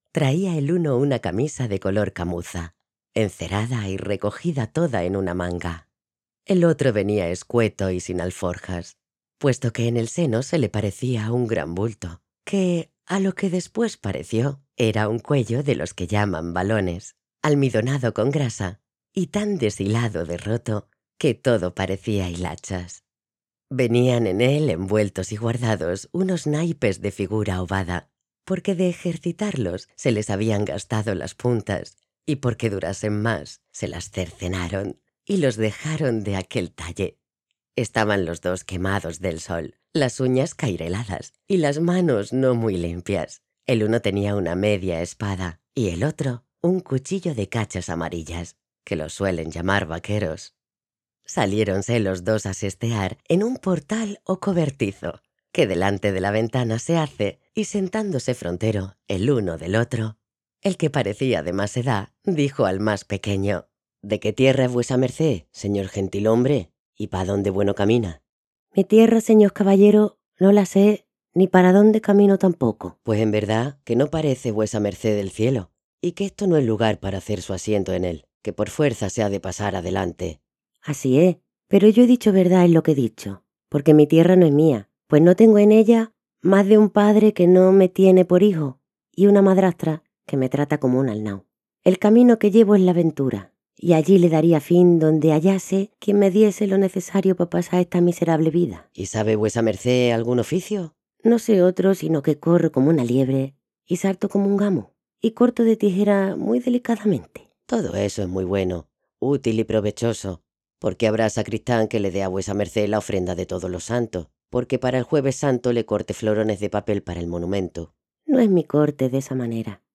Género: Femenino